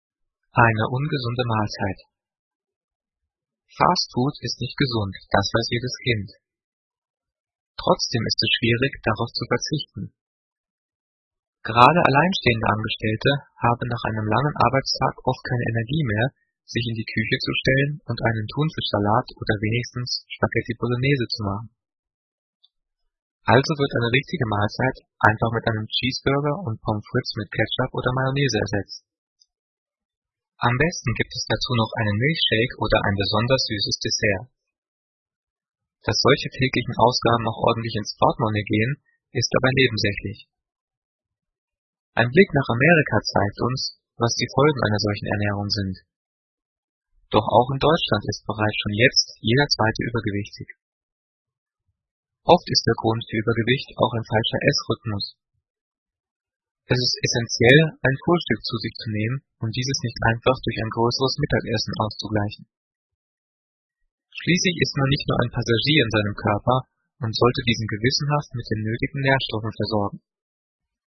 Diktat: "Eine ungesunde Mahlzeit" - 7./8. Klasse - Fremdwörter
Gelesen: